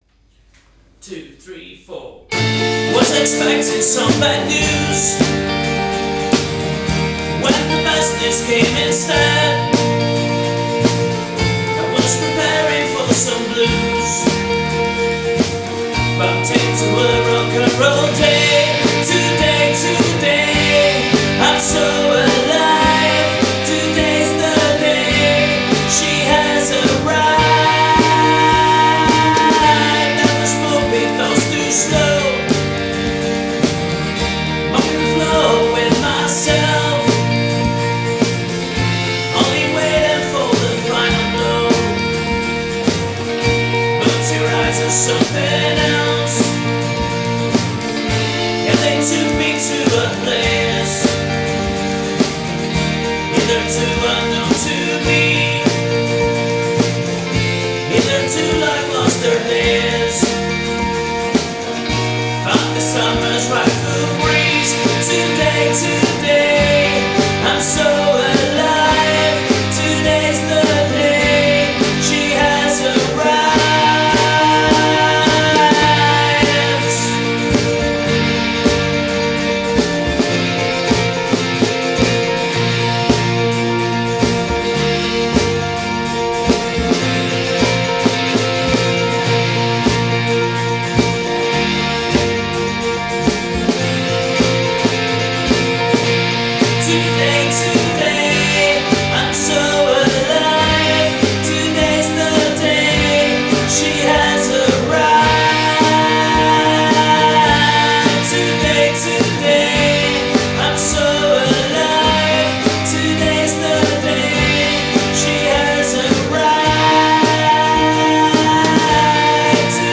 vocals, guitars, bass, drums, percussion